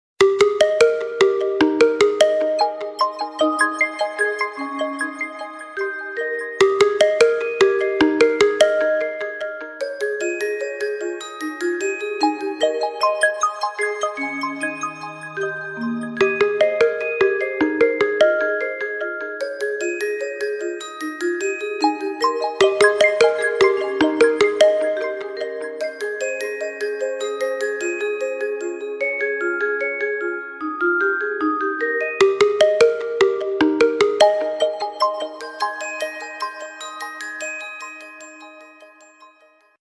Kategoria Alarmowe